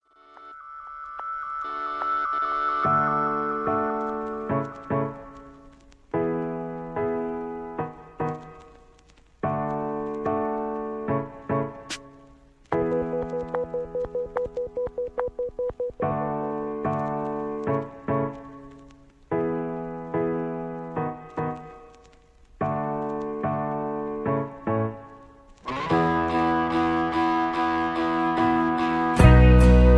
backing tracks, karaoke, sound tracks, rock, r and b